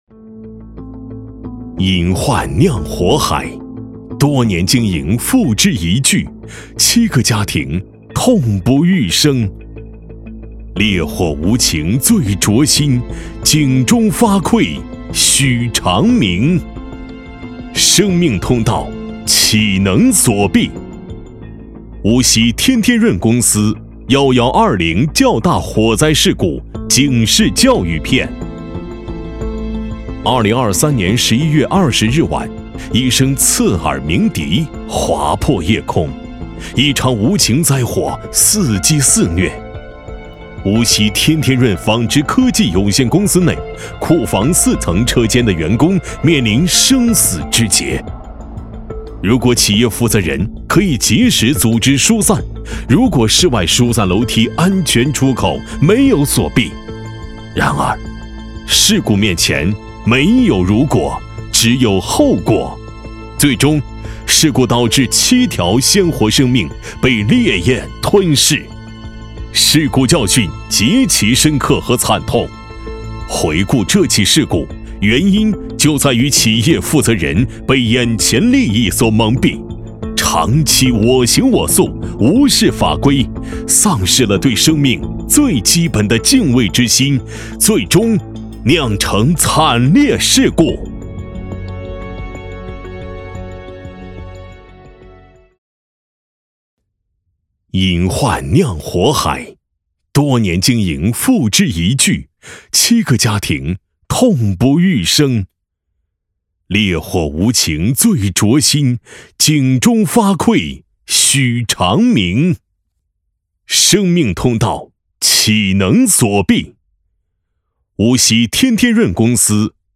国语配音
男198--专题-警示片--生命通道-岂能锁闭.mp3